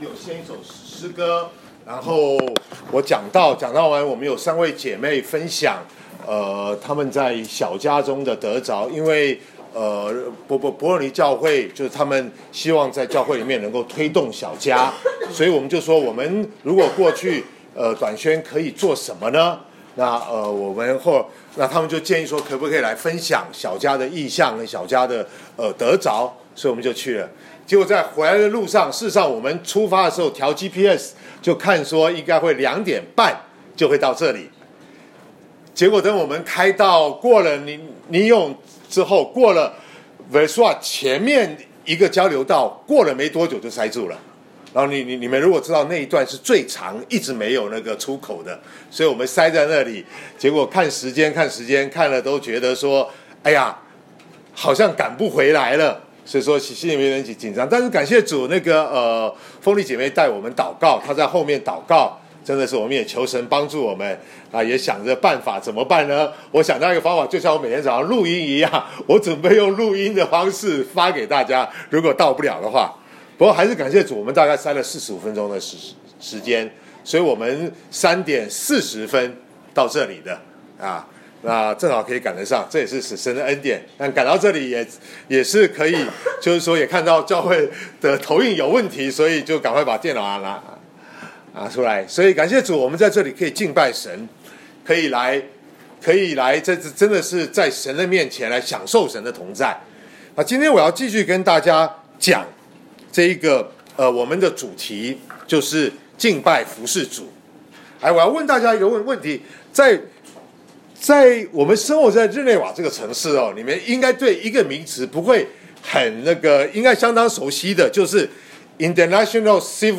2019年2月17日主日讲道：为什么要服事主？